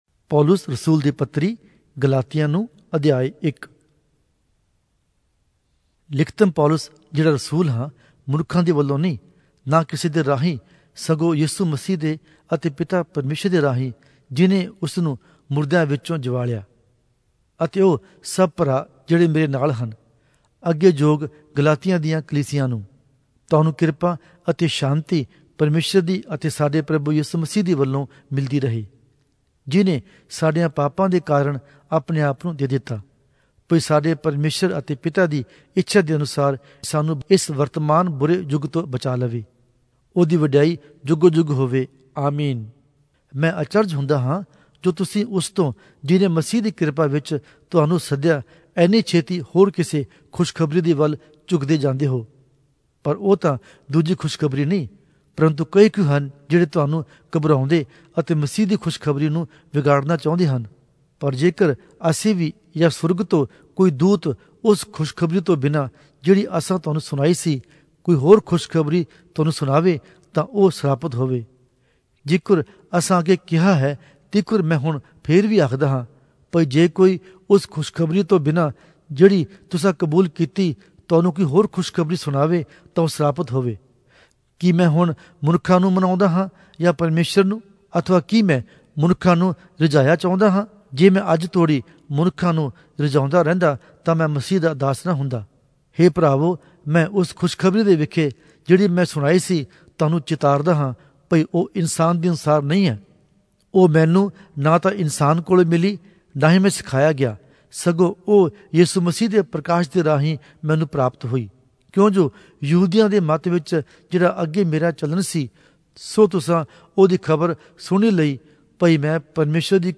Punjabi Audio Bible - Galatians 4 in Gntbrp bible version